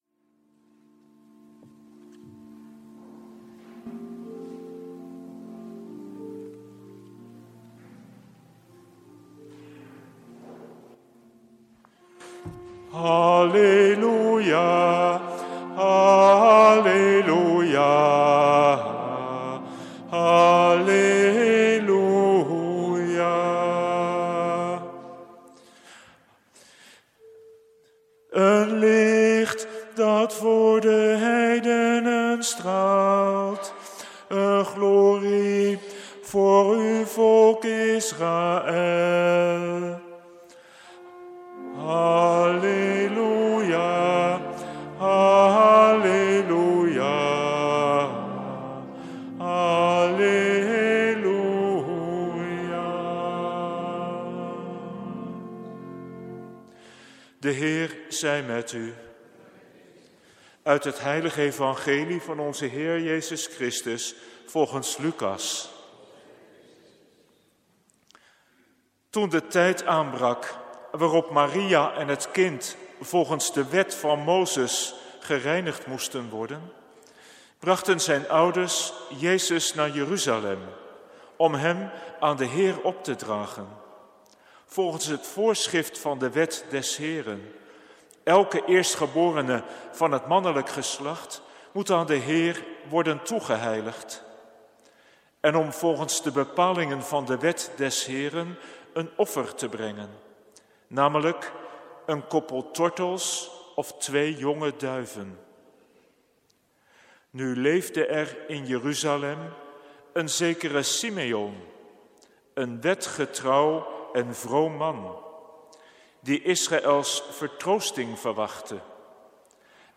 Lezingen
Eucharistieviering beluisteren (MP3)